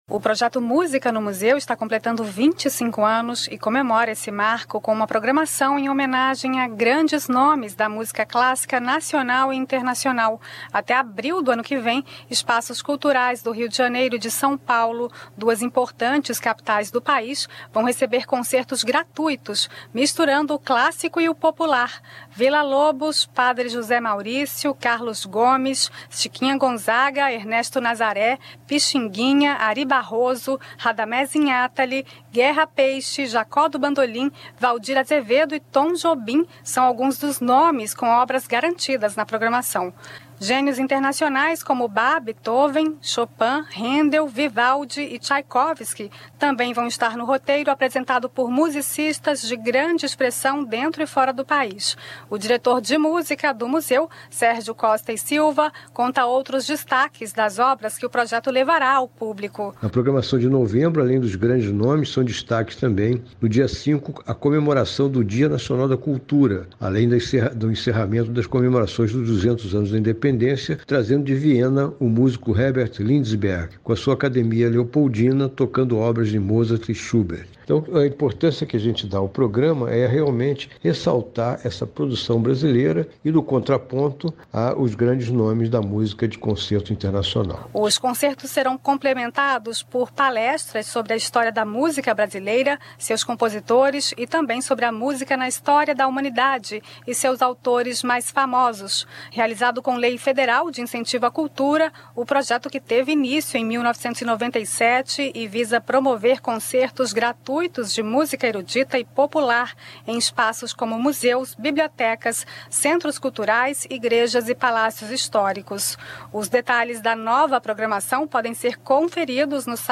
Repórter da Rádio Nacional